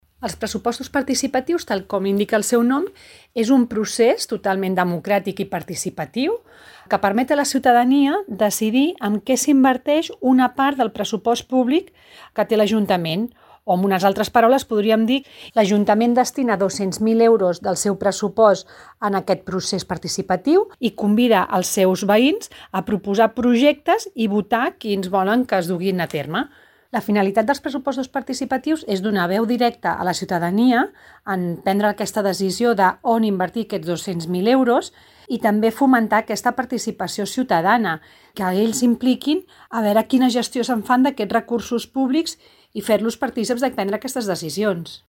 Àngels Soria, regidora de Teixit Associatiu, Cooperació i Participació